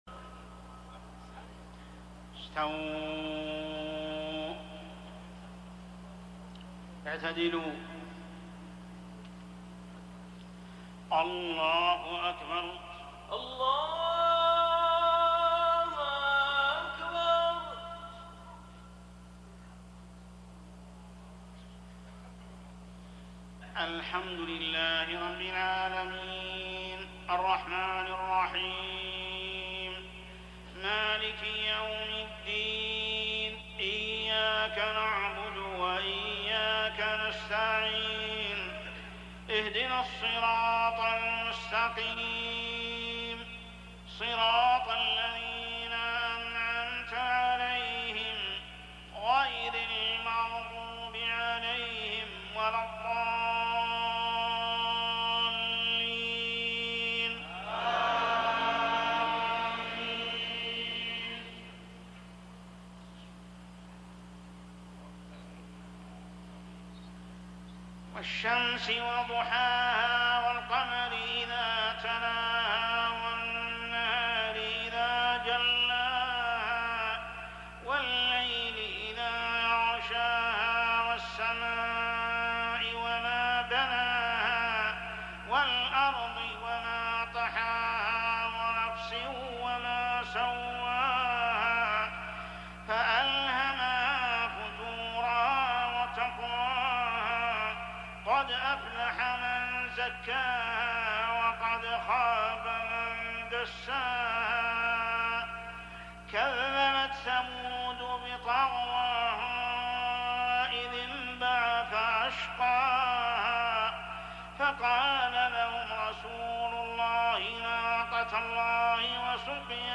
صلاة العشاء رمضان عام 1420هـ سورتي الشمس و القدر > 1420 🕋 > الفروض - تلاوات الحرمين